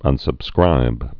(ŭnsŭb-skrīb)